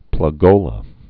(plŭg-ōlə)